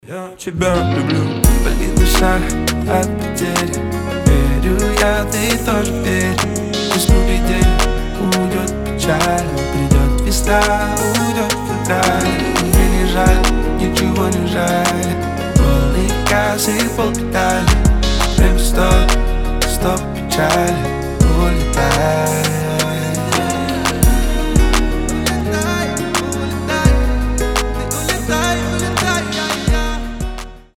• Качество: 320, Stereo
гитара
лирика
красивый мужской голос
дуэт
Cover